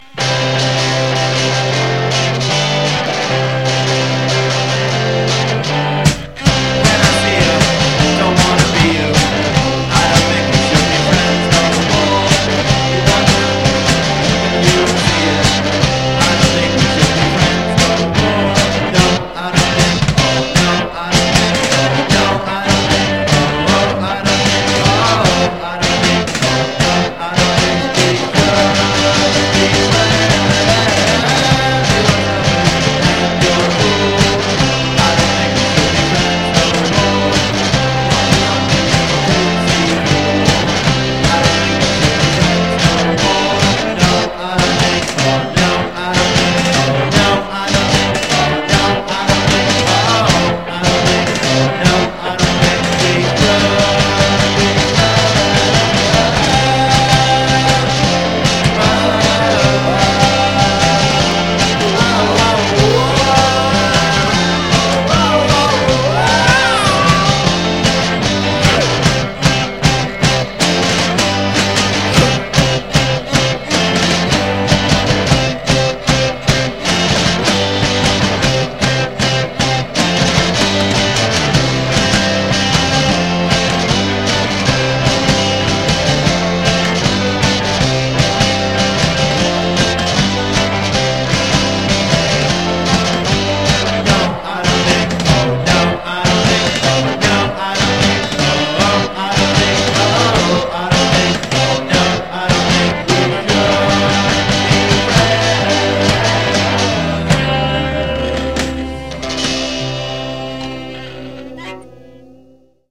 rimaste per ora in forma di demo
tra garage improvvisato e sguaiata bassa fedeltà.